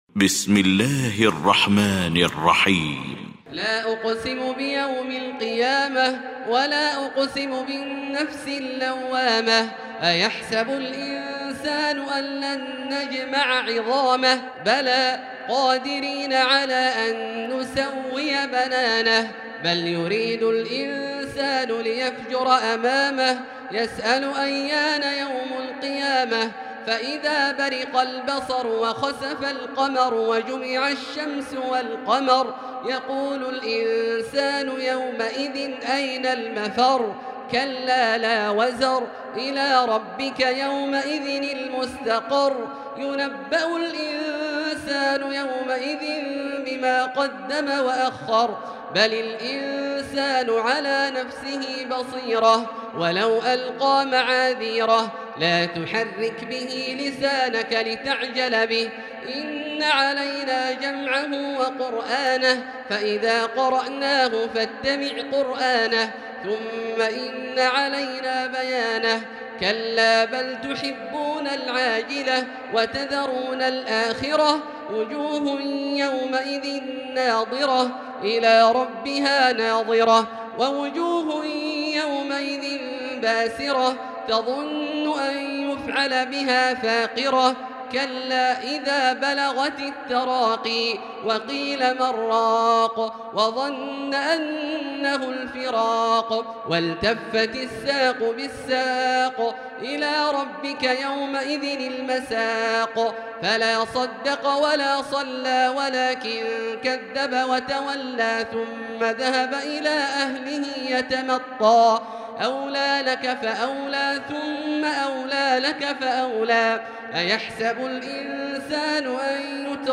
المكان: المسجد الحرام الشيخ: فضيلة الشيخ عبدالله الجهني فضيلة الشيخ عبدالله الجهني القيامة